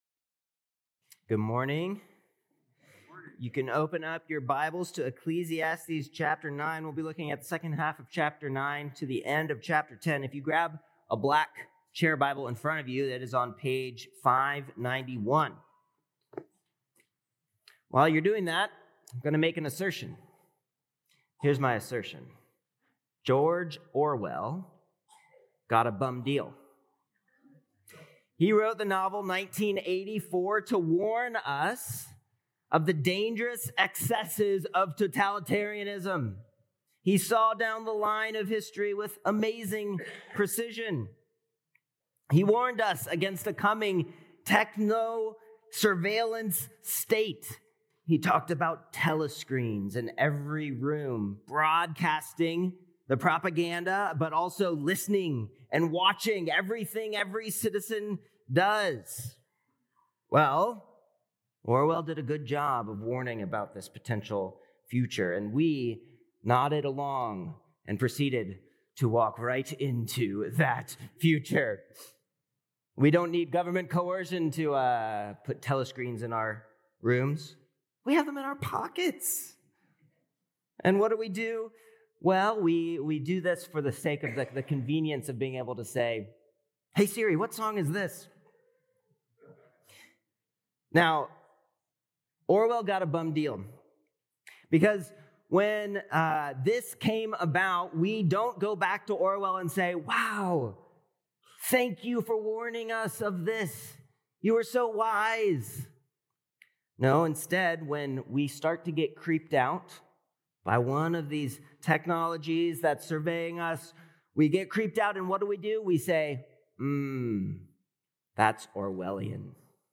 May 11th Sermon